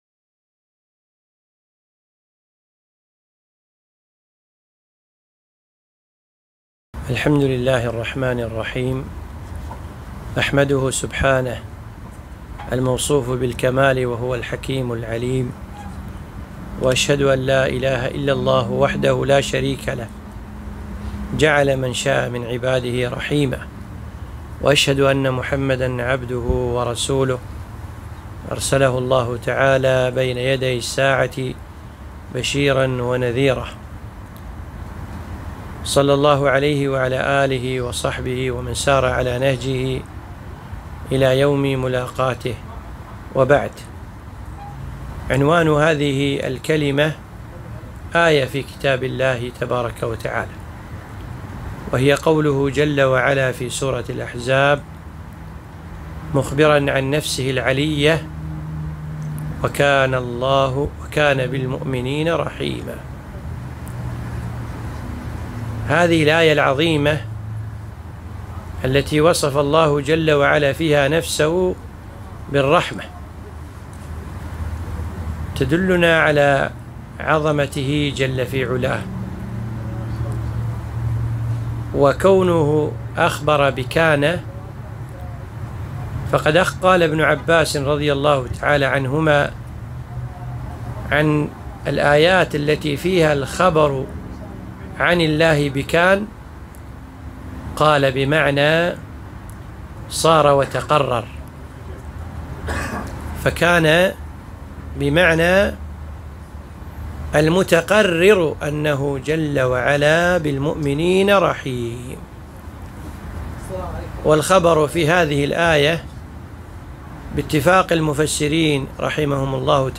محاضرة - (( وكان بالمؤمنين رحيما ))